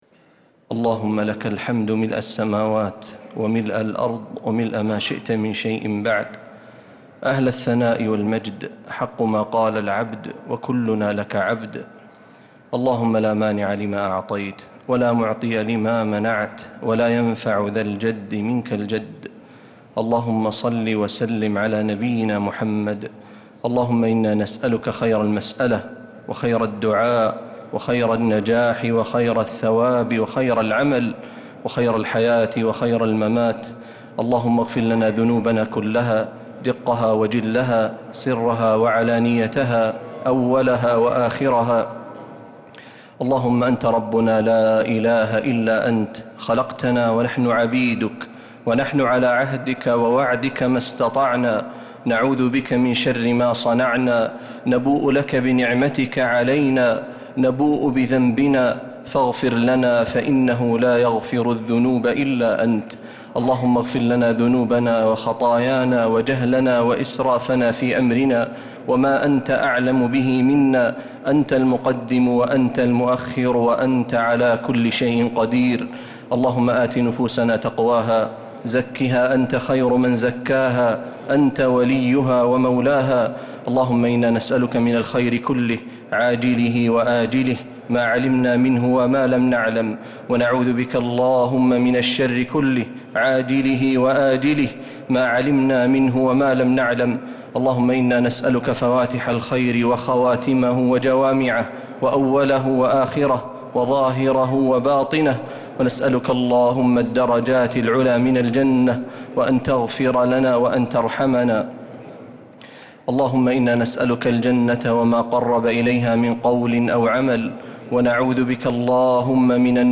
دعاء القنوت ليلة 3 رمضان 1446هـ | Dua 3rd night Ramadan 1446H > تراويح الحرم النبوي عام 1446 🕌 > التراويح - تلاوات الحرمين